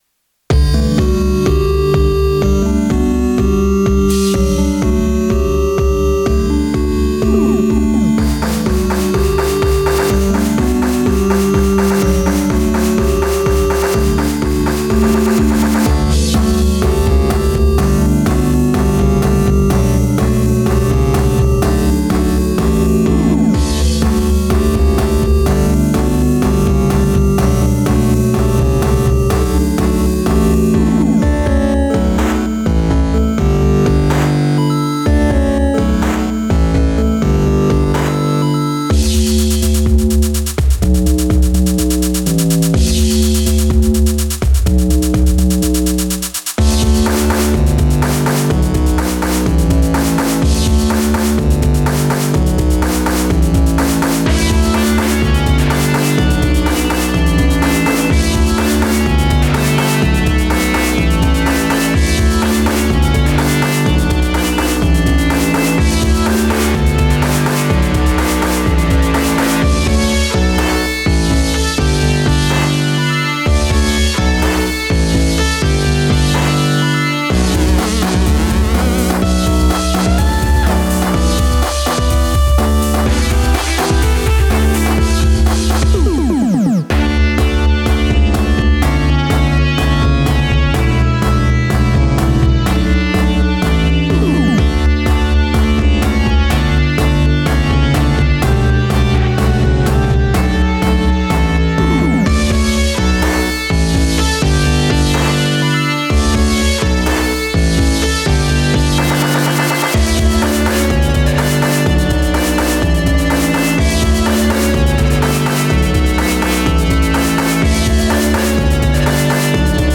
a chiptune track